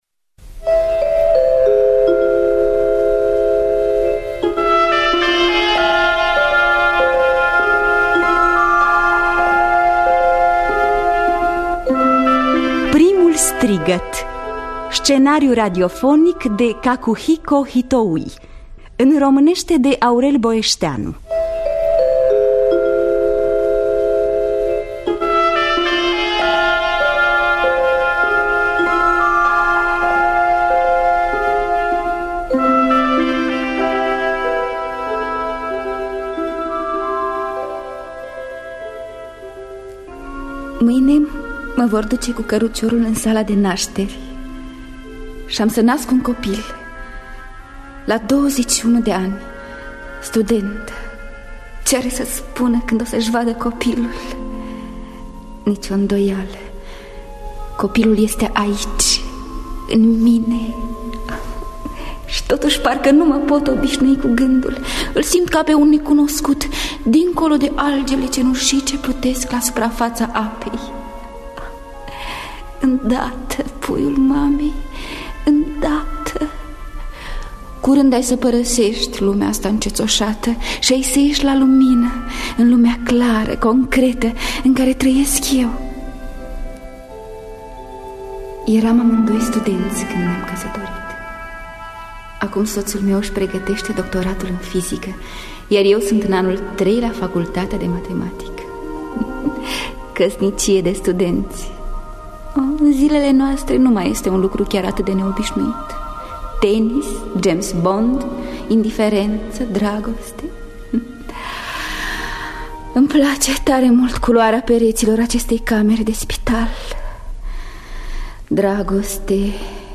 Primul strigăt de Cacuhiko Hitoui – Teatru Radiofonic Online
Înregistrare din anul 1969.